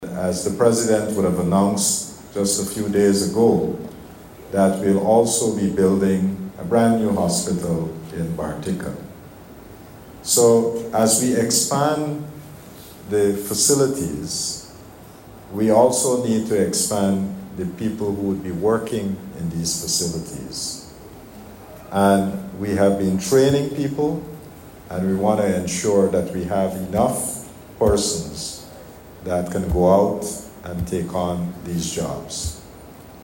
Minister of Health, Dr. Frank Anthony, while delivering the keynote address congratulated the graduates and further highlighted the Government’s commitment to expanding infrastructure in the health sector, and also improving service delivery by building a more trained workforce.